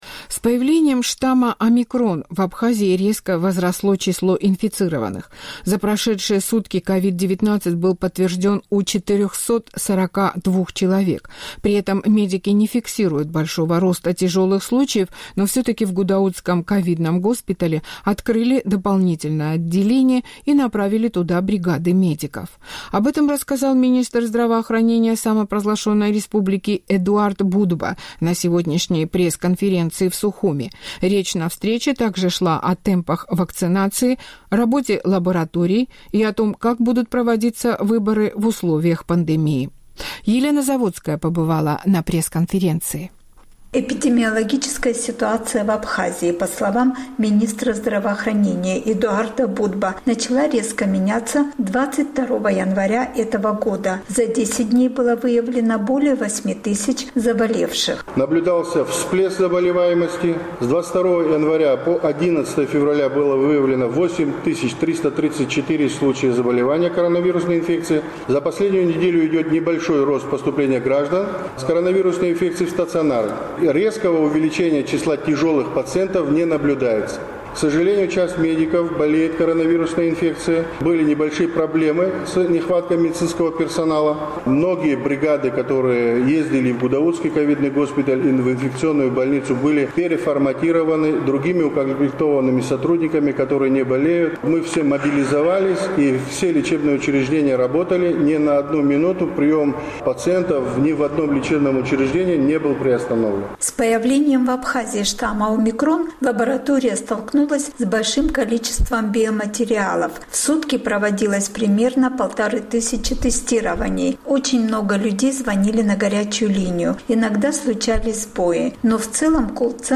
Об этом рассказал министр здравоохранения Эдуард Бутба на сегодняшней пресс-конференции в Сухуме. Речь на встрече также шла о темпах вакцинации, работе лабораторий и о том, как будут проводиться выборы в условиях пандемии.